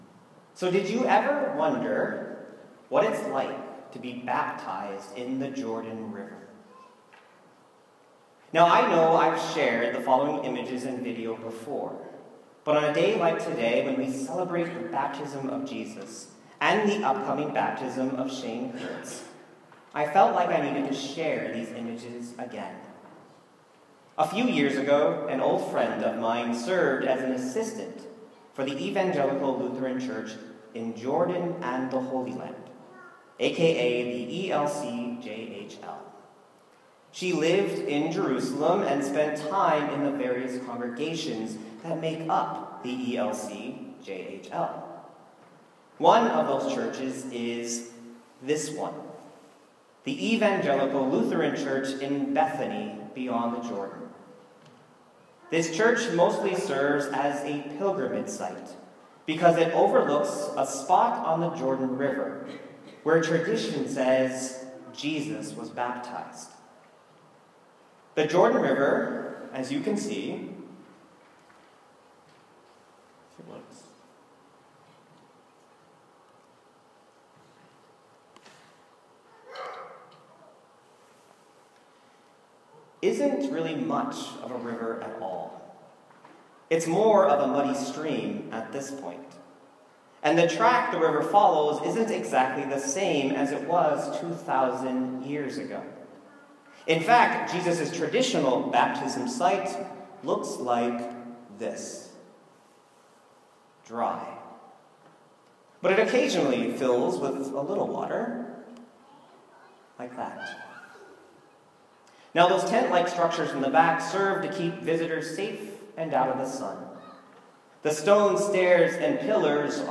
Mark 1:4-11 My sermon from the Baptism of Jesus (January 7, 2018) on Mark 1:4-11.